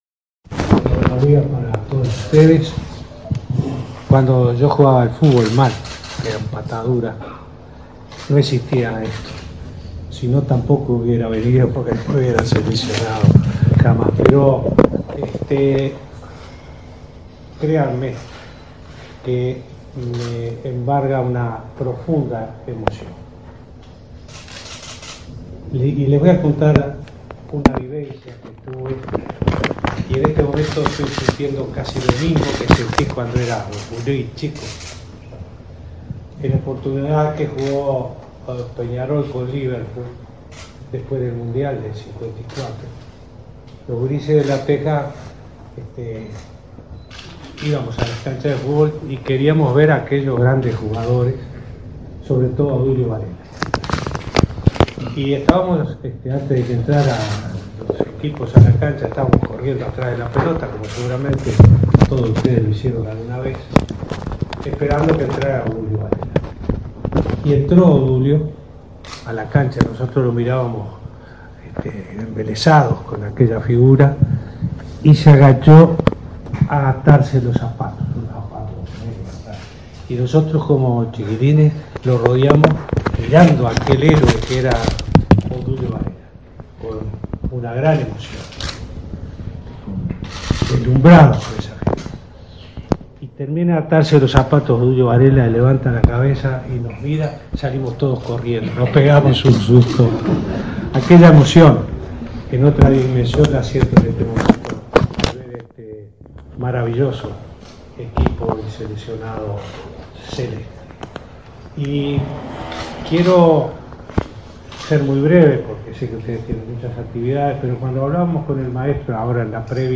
El presidente Tabaré Vázquez entregó este martes en el Complejo Celeste el Pabellón Nacional a la selección de fútbol que participará en el mundial de Rusia. El mandatario subrayó que no hay institución pública ni privada que tenga tanto respaldo de la gente como la selección.
“Otro sentir que tenemos los uruguayos es la confianza en todos ustedes”, les dijo.